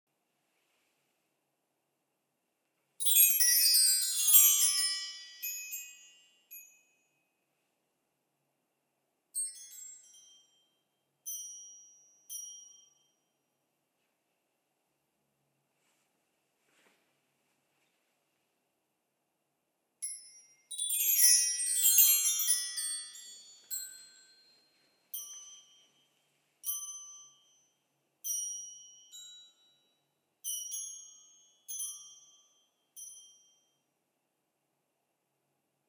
Diese Chimes zeichnen sich durch eine besonders gute Resonanz und einen langen Nachhall aus. Genießen Sie den kristallklaren, brillanten Klang.
Die Spiral Chimes können entweder fest an der Spitze des Aluminiumrings aufgehängt oder mit einer Hand gehalten und mit der anderen gespielt werden.